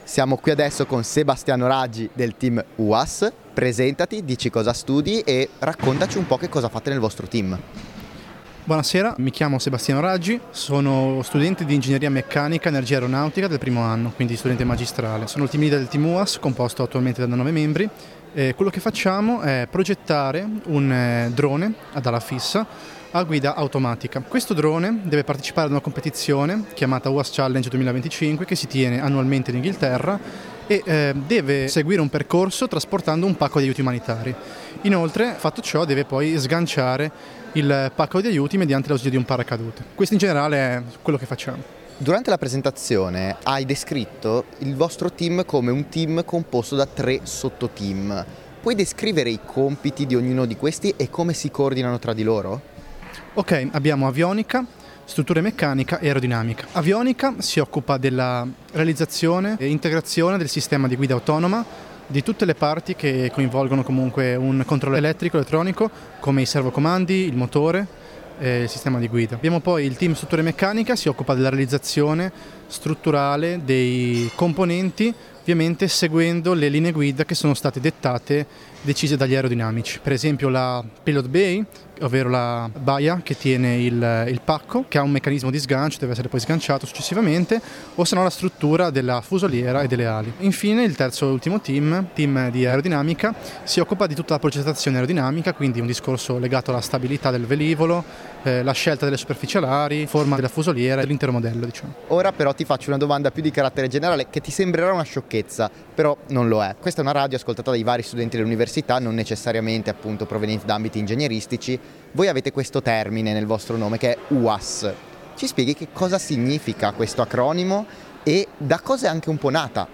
Intervista di